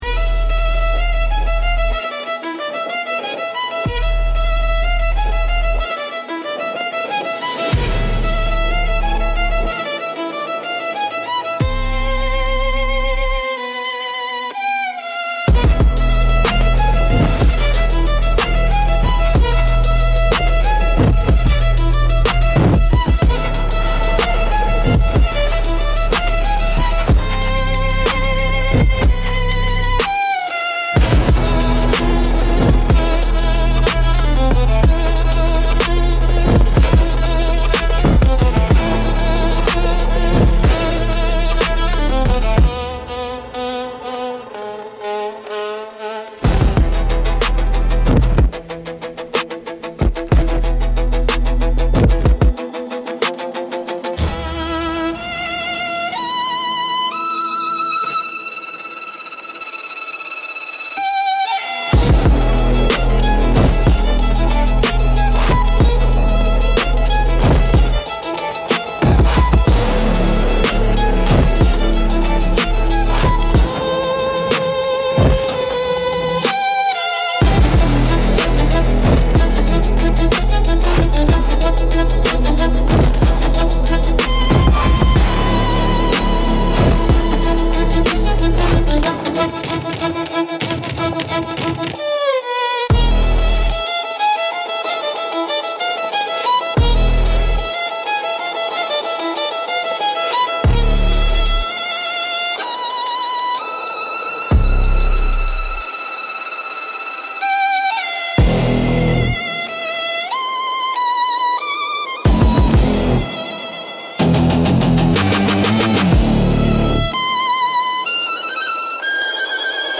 Instrumental - Dark Pop Trap x Warning Bells Bass